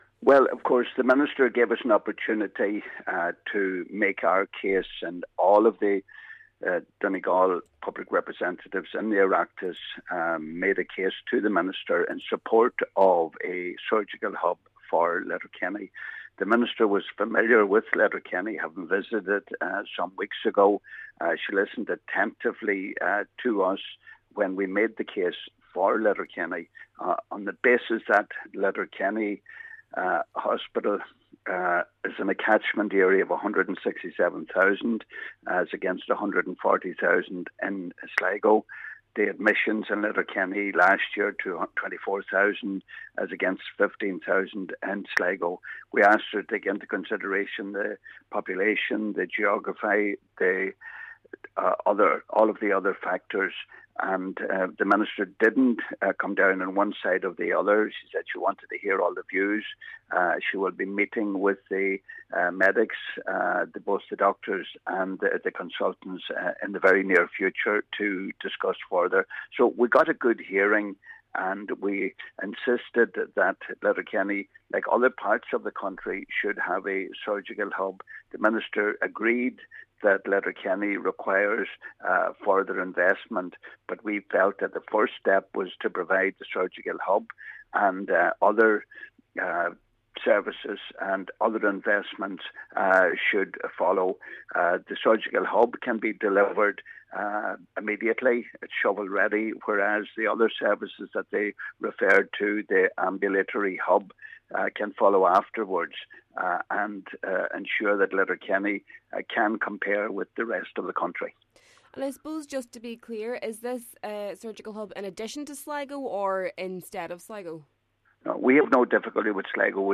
Donegal Deputy Pat the Cope Gallagher says that the Minister was open to hearing all sides of the story, but remained tight-lipped on any final decisions: